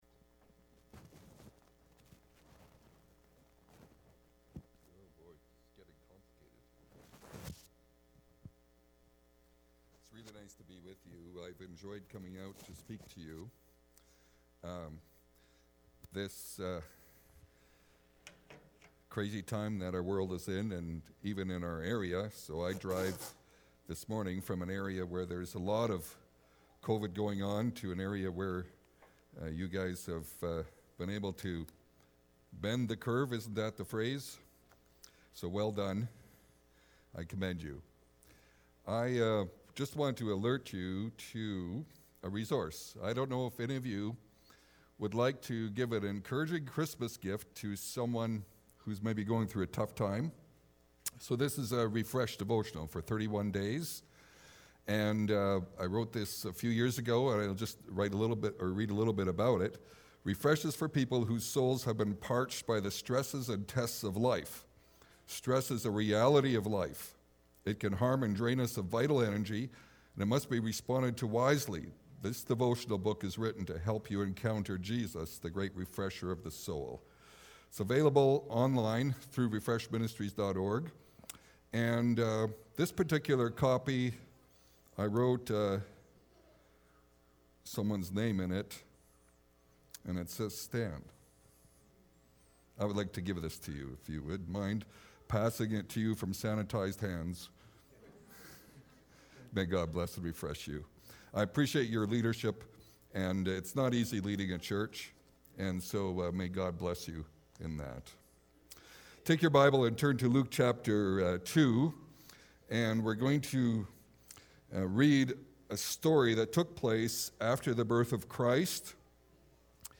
Dec-6-2020-sermon.mp3